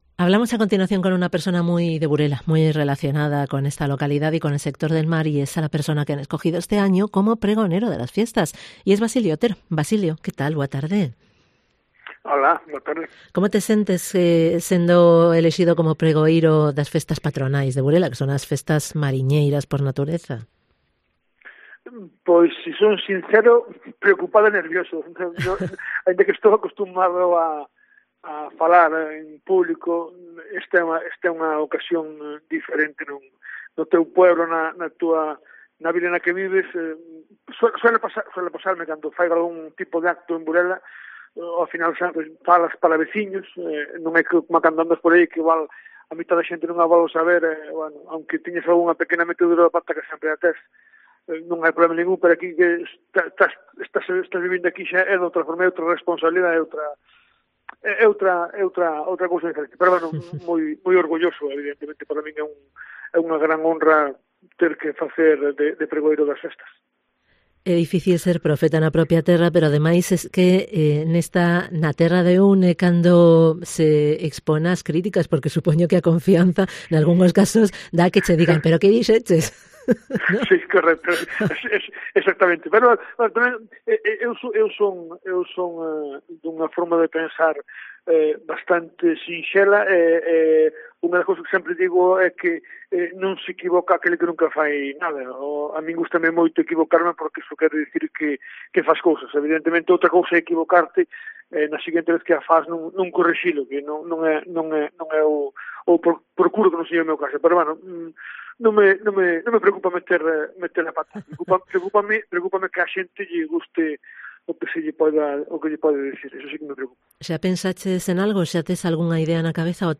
Del pregón, de la situación actual del sector pesquero y de lo debatido en EXPOMAR hablamos con él en esta entrevista.